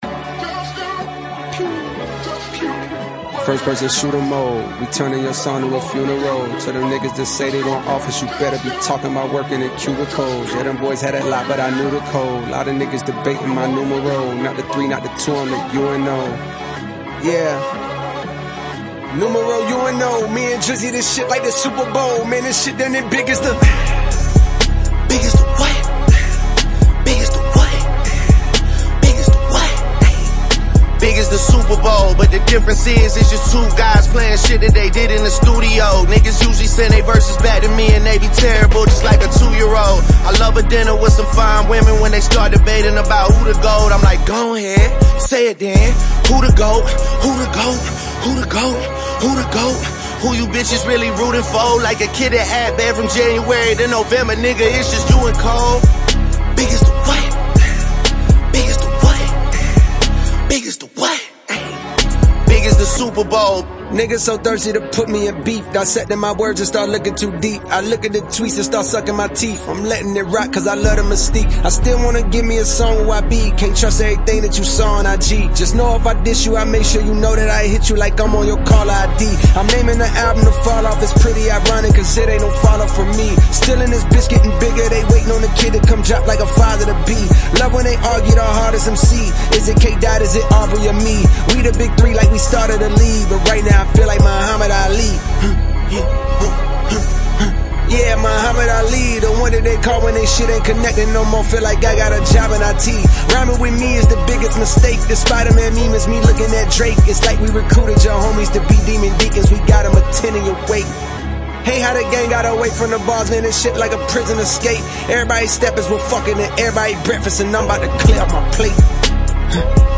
канадского рэпера
американского рэпера